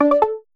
notification_sounds
tintong.mp3